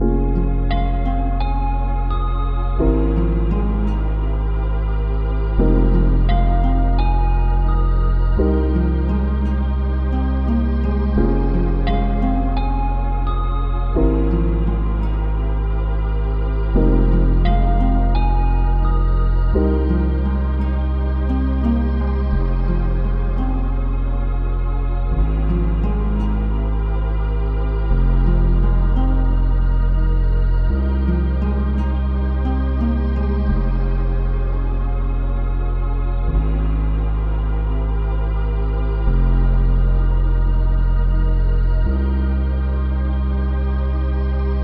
• High-Quality RnB Samples 💯